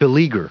515_beleaguer.ogg